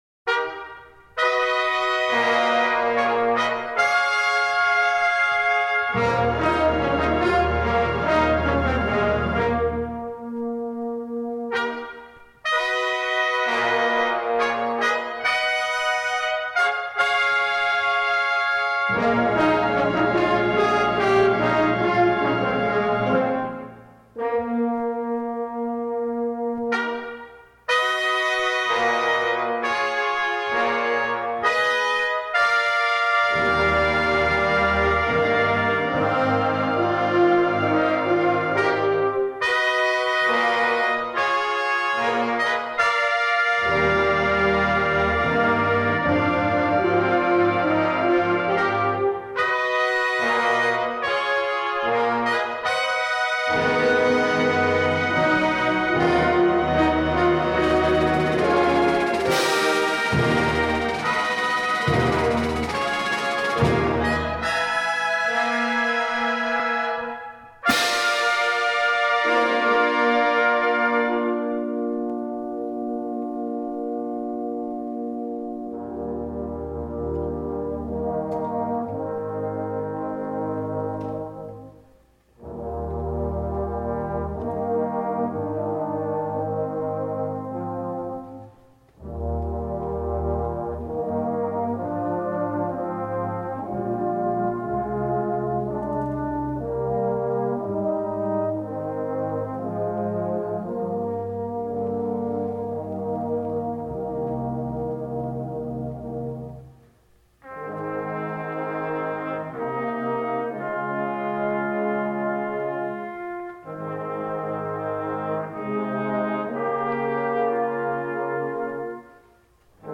Twenty-five of the performers are music majors.